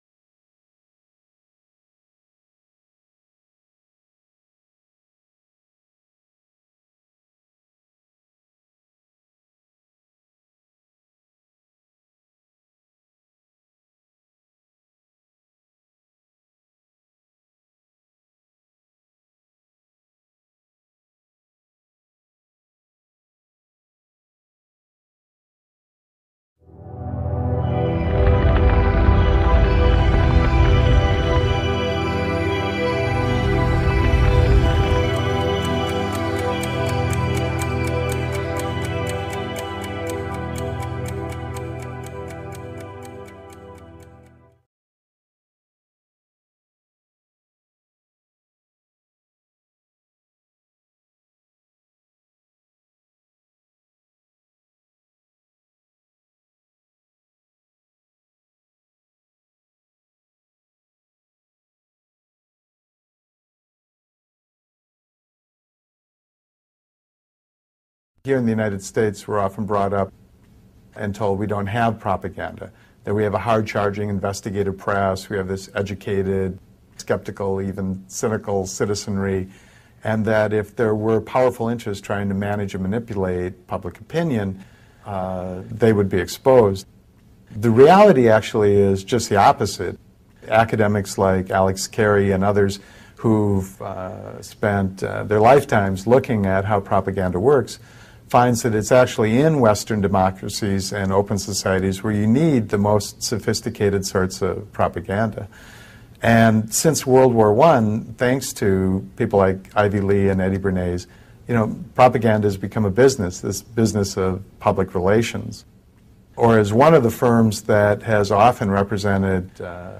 יצירת חדשות על ידי המשטר! סרט דוקומנטרי